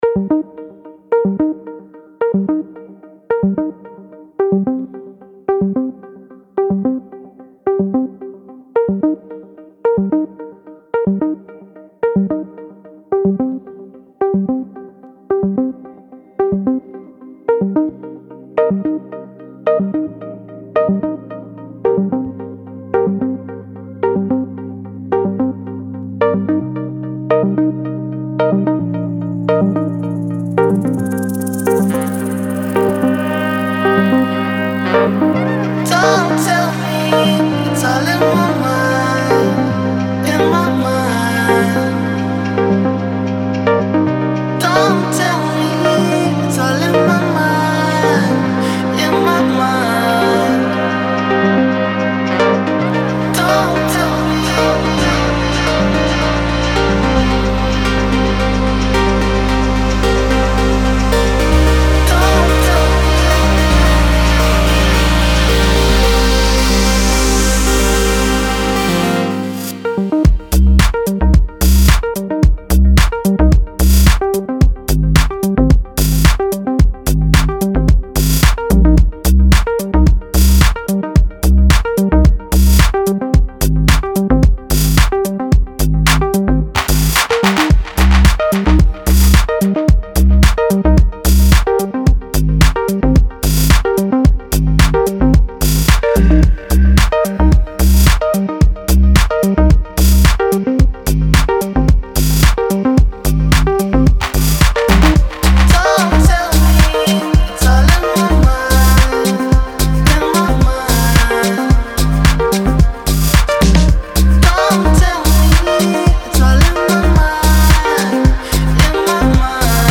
Настроение песни — меланхоличное, но с надеждой на лучшее.